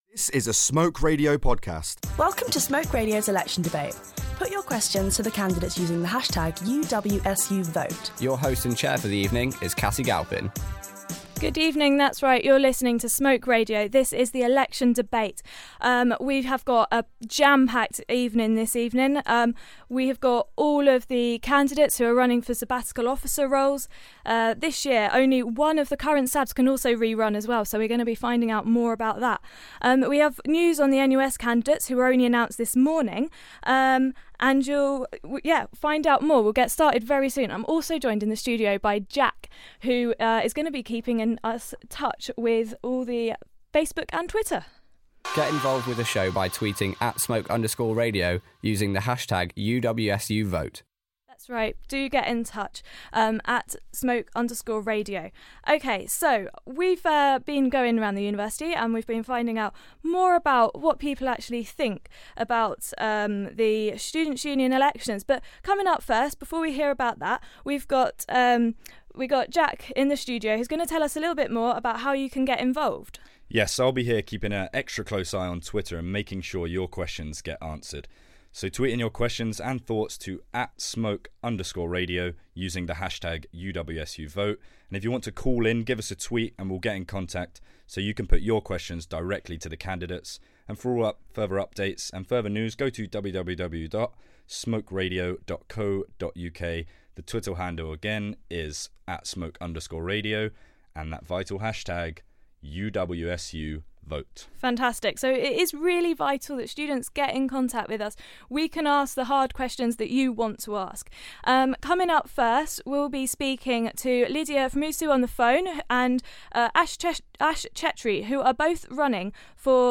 Smoke Radio Election Debate
Smoke Radio's Election Debate, aired on the 19th February. We hear from all of the candidates and students pitch questions to them via Twitter and on the phone.